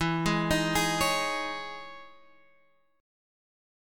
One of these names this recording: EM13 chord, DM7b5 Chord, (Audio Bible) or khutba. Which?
EM13 chord